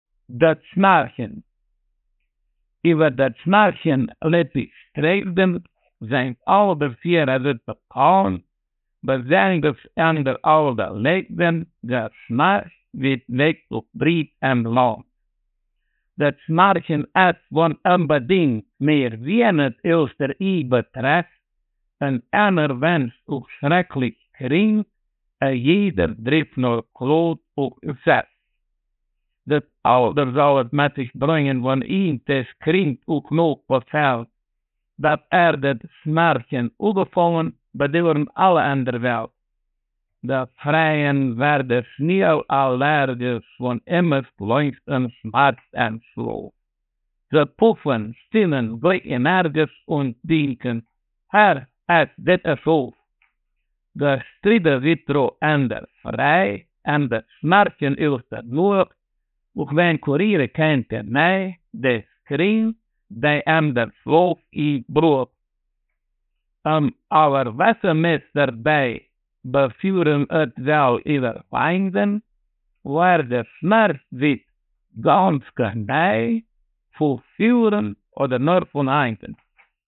„Det Schnarchen“ wurde bei der Tagung der Kulturreferenten vom 3. bis 5. Dezember 2010 in Augsburg vorgetragen. Im Mittelpunkt der Tagung stand die siebenbürgisch-sächsische Mundart.
Ortsmundart: Mediasch